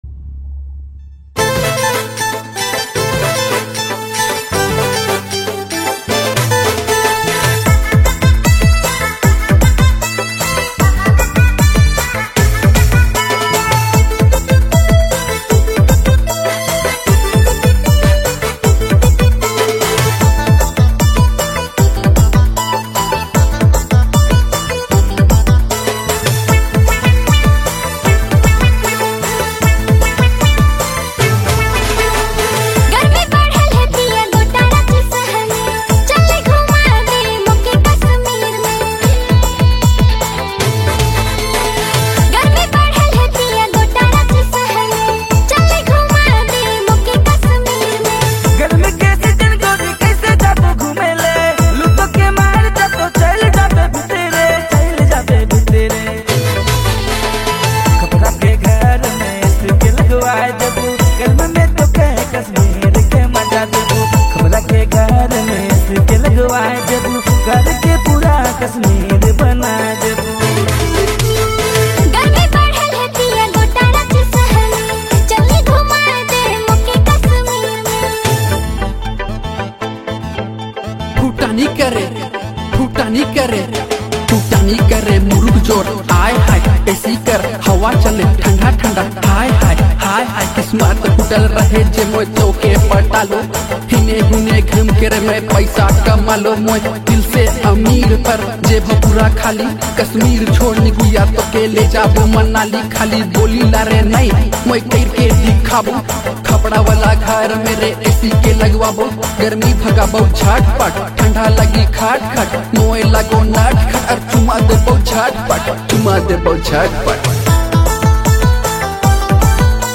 Nagpuri